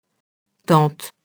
tante [tɑ̃t] nom féminin (altér. de l'anc. fr. ante, lat. amita, tante paternelle)